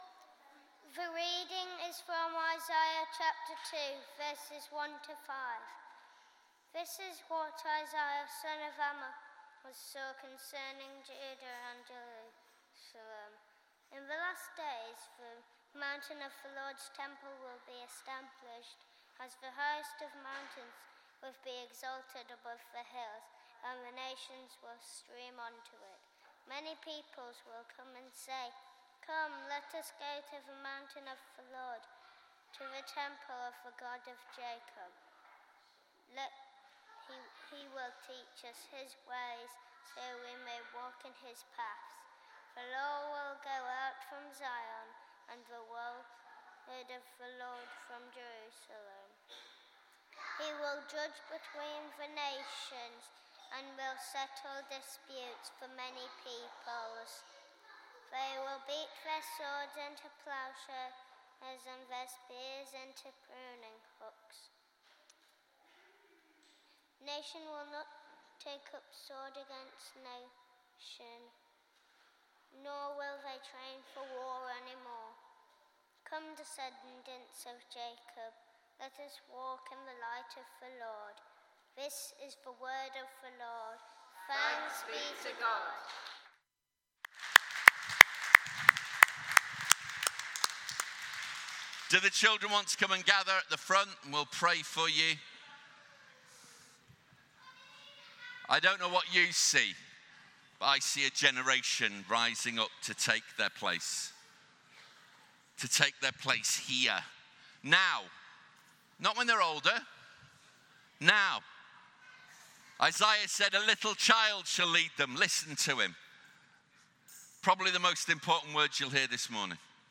Passage: Isaiah 2: 1-5 Service Type: Sunday Morning